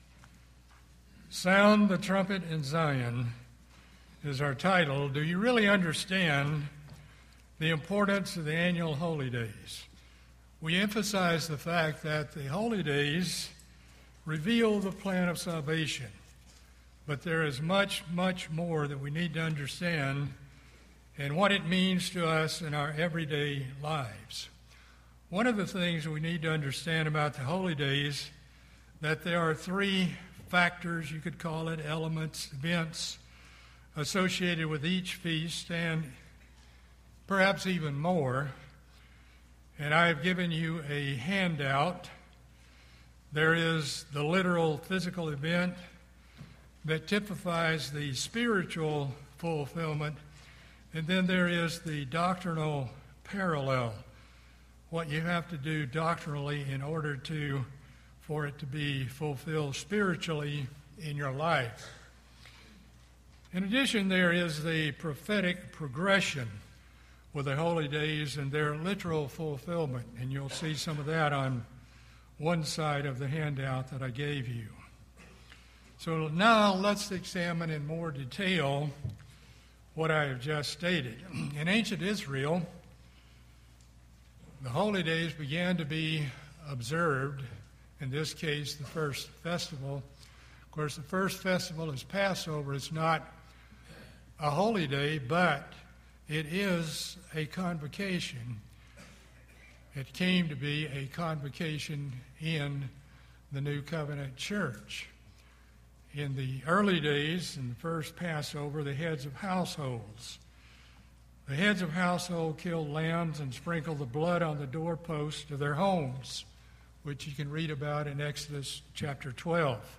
There are various significant factors associated with each feast or holy day—the physical event, the doctrinal parallel, the prophetic progression and/or the literal fulfillment associated with each holy day. In this sermon, you will gain a deeper understanding of the spiritual significance and meaning of all of God’s holy days and their importance in our daily lives.